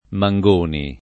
[ ma jg1 ni ]